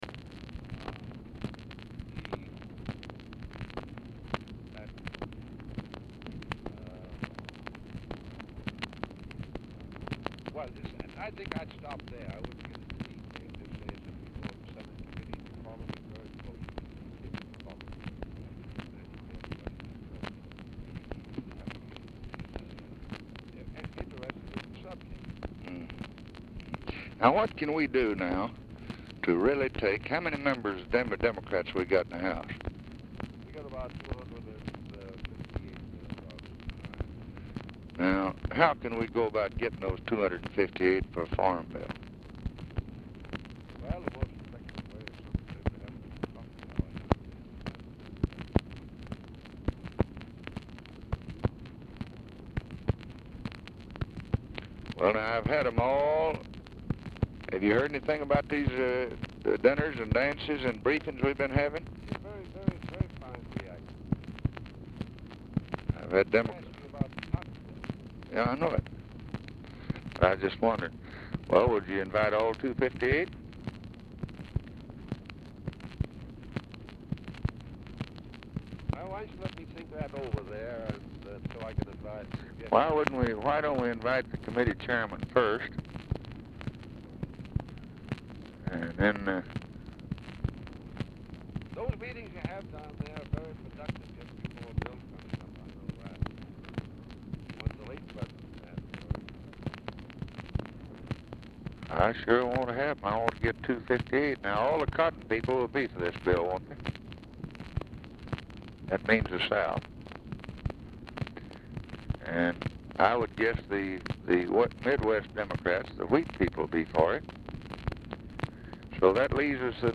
Telephone conversation # 2387, sound recording, LBJ and JOHN MCCORMACK
POOR SOUND QUALITY; MCCORMACK DIFFICULT TO HEAR
Format Dictation belt
Location Of Speaker 1 Oval Office or unknown location